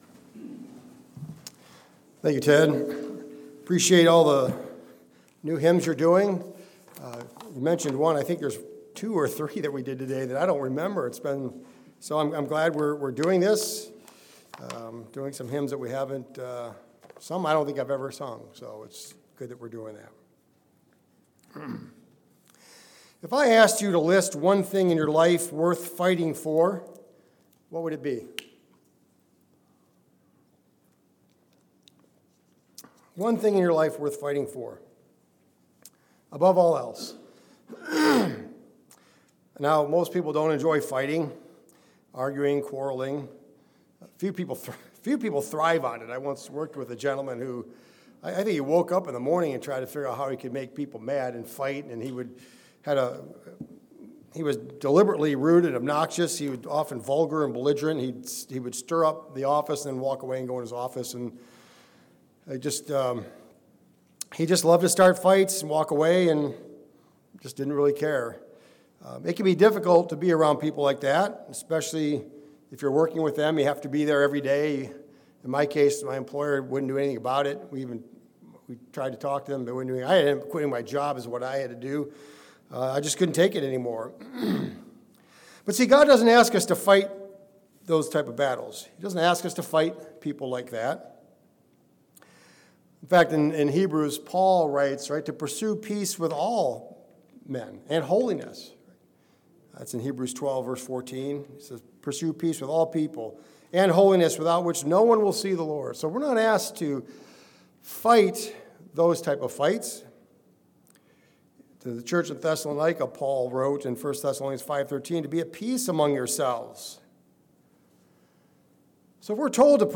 Sermons
Given in Mansfield, OH